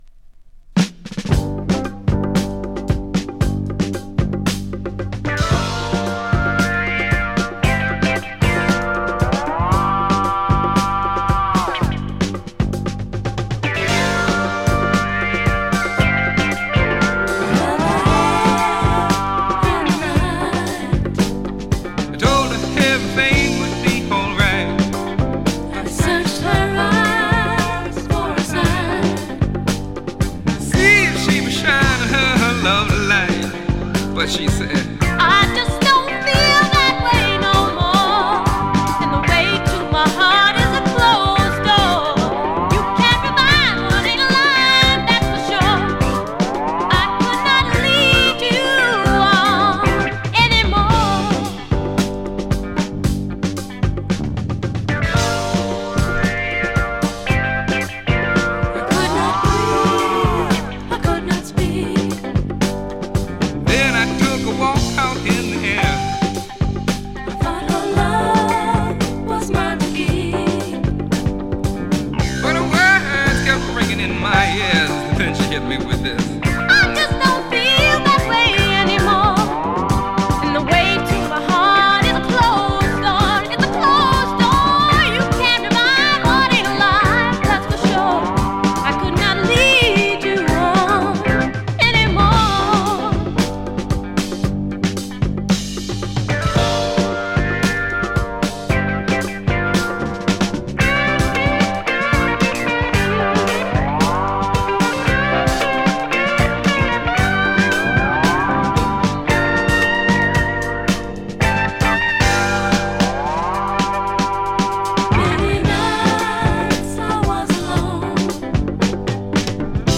【JAZZ FUNK】 【FUSION】
ソウル・フュージョン！